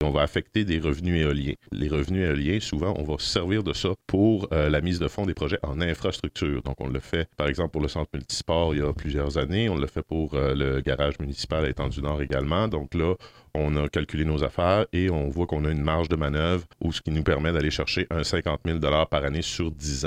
Les explications du maire Antonin Valiquette.